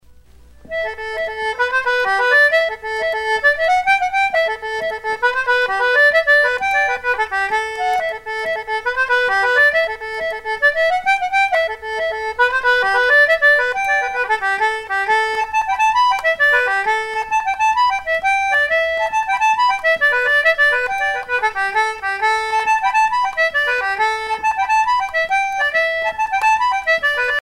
Sea chanteys and sailor songs
Pièce musicale éditée